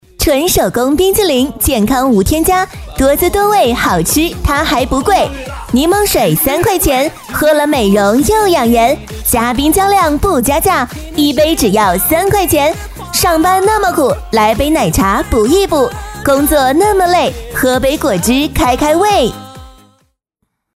女19号配音师
温柔知性是我，活泼可爱也可以是我